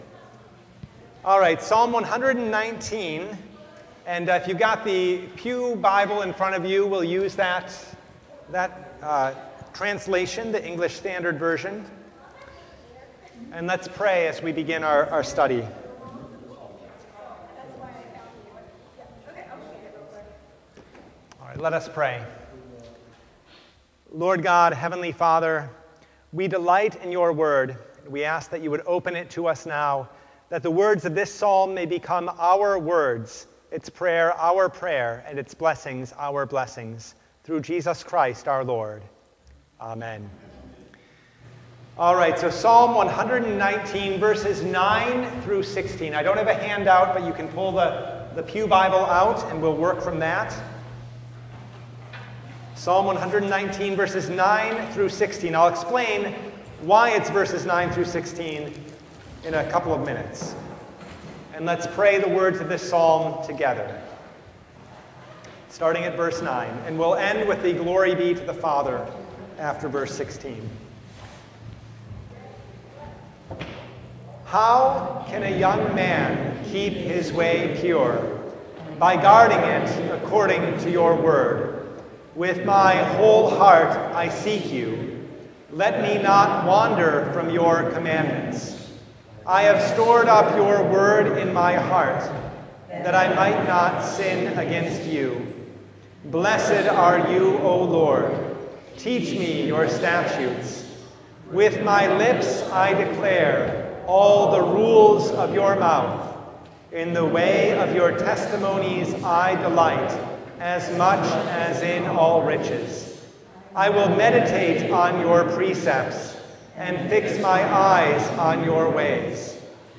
Psalm 119:9-16 Service Type: Bible Study How can a young man keep his way pure?